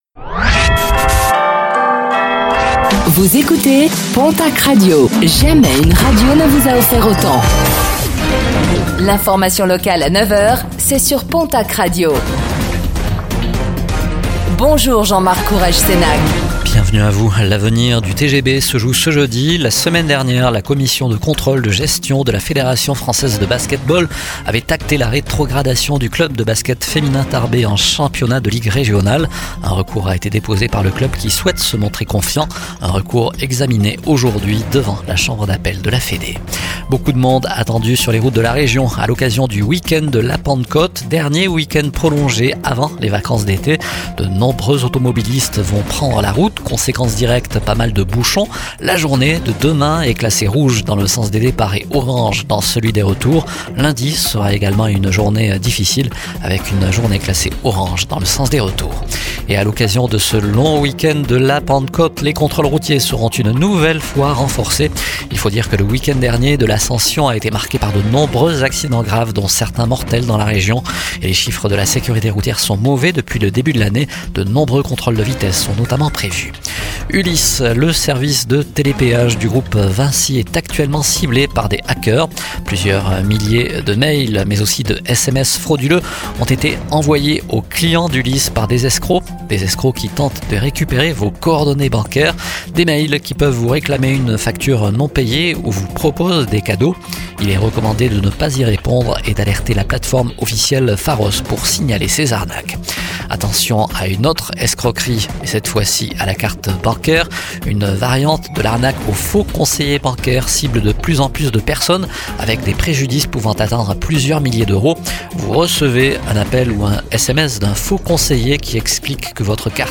Infos | Jeudi 05 juin 2025